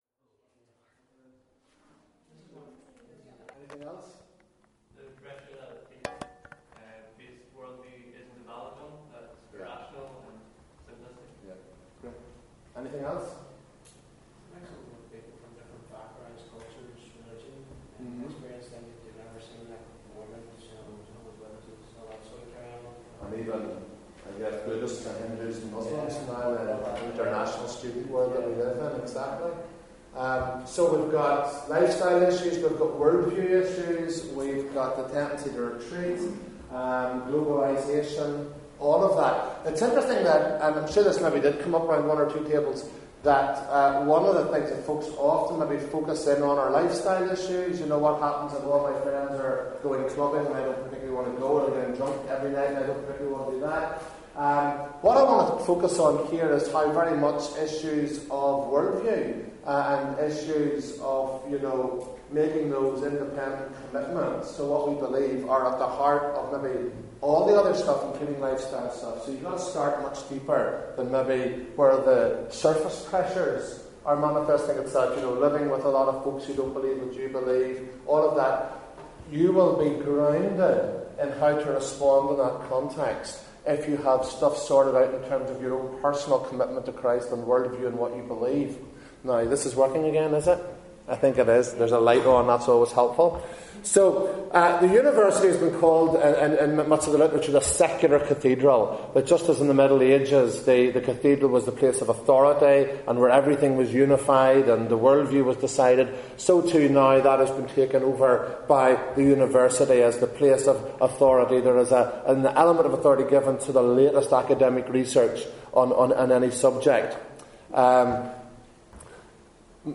This recording is taken from the Transform Gathering which took place in the Cathedral Quarter, Belfast from 1st-2nd April 2016.